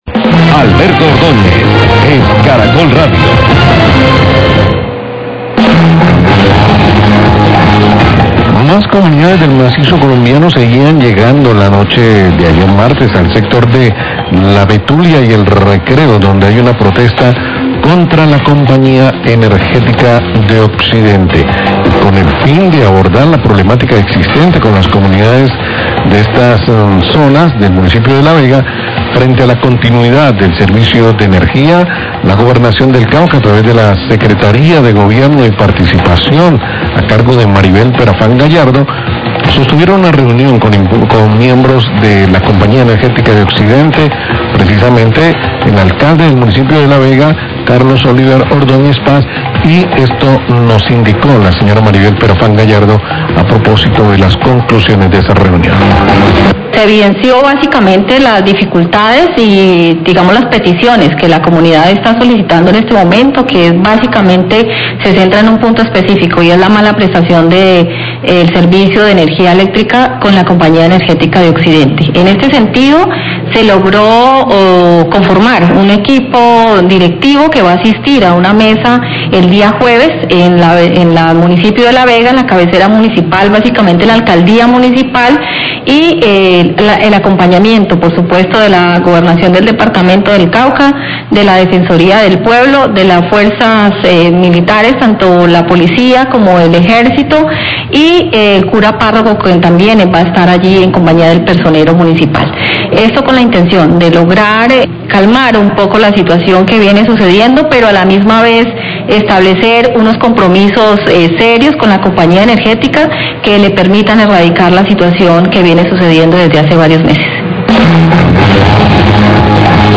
Radio
La Secretaría de Gobierno del Cauca, Maribel Perafán, sostuvo una reunión con miembros de la Compañía Energética y el Alcalde de La Vega, Carlos Ordoñez, por la protesta de las comunidades de La Betulia y El Recreo, frente a la continuidad  del servicio de energía. Declaraciones  de la Secretaria de Gobierno, Maribel Perafán y del Gobernador del Cauca, Oscar Campo.